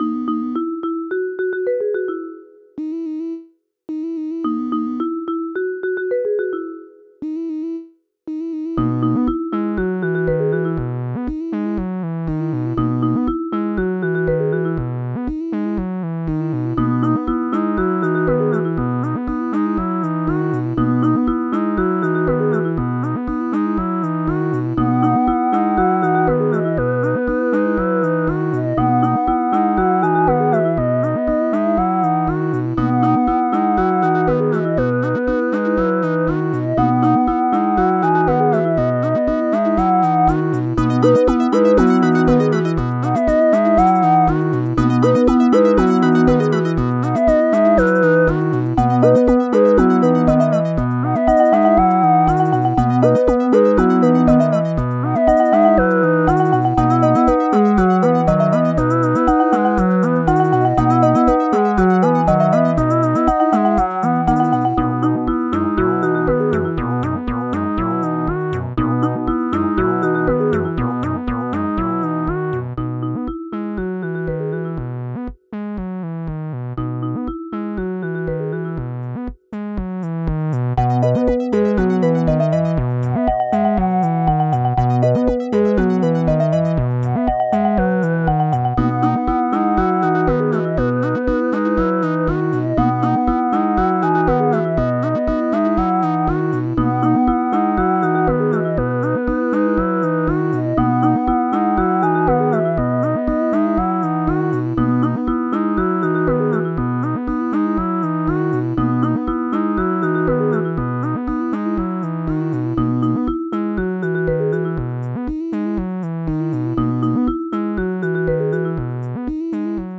丸い音色のシンセで様々なメロディを奏でます。
リズミカル、明るい、楽しい、不思議